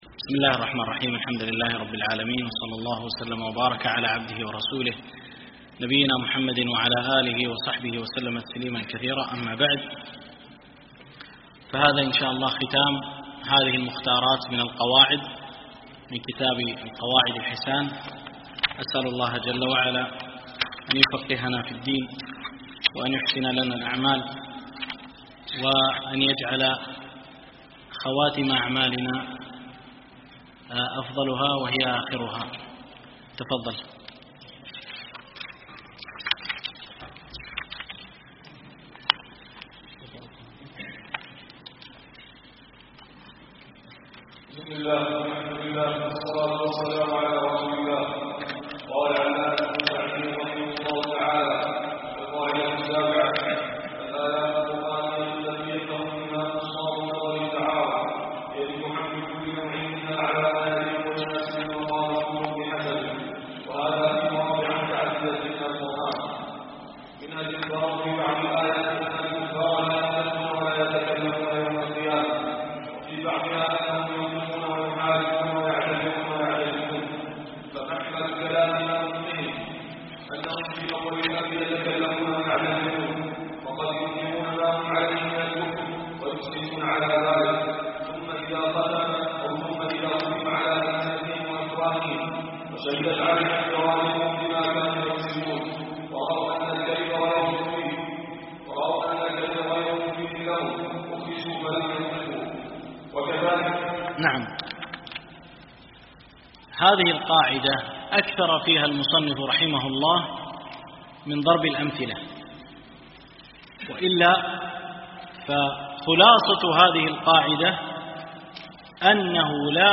شرح القواعد الحسان في تفسير القرآن ـ الدرس الرابع
دورة الإمام مالك العلمية الثانية بدبي
دروس مسجد عائشة (برعاية مركز رياض الصالحين ـ بدبي)